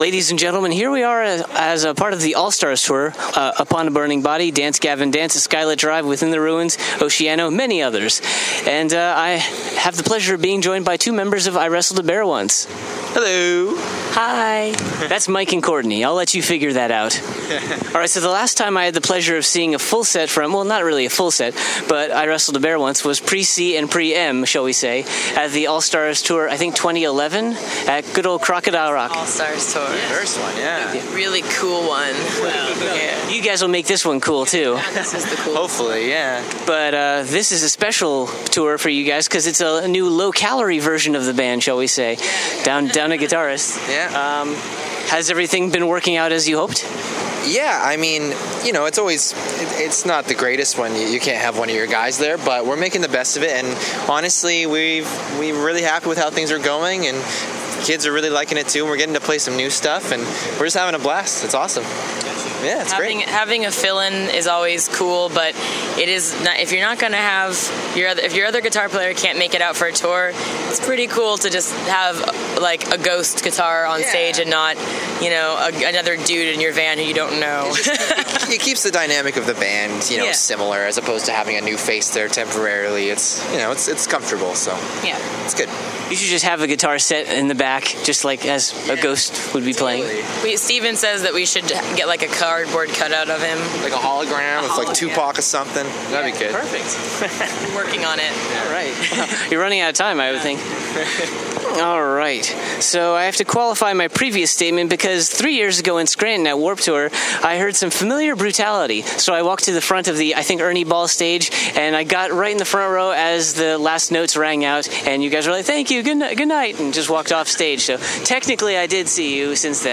Exclusive: iwrestledabearonce Intereview (2015)